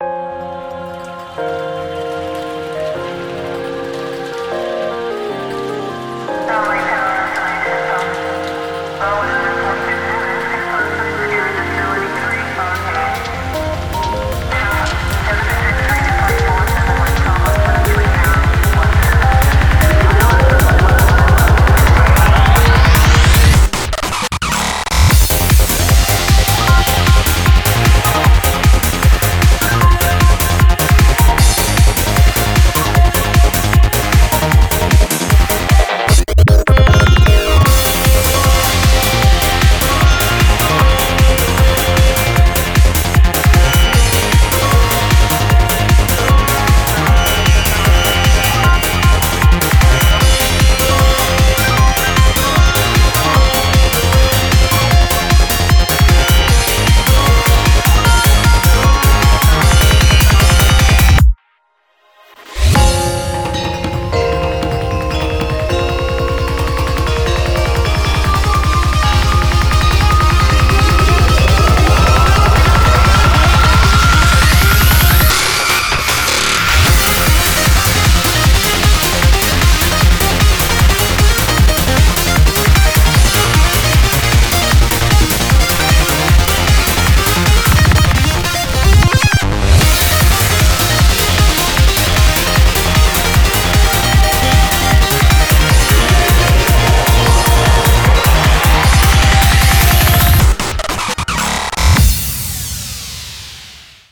BPM153-306
Audio QualityPerfect (High Quality)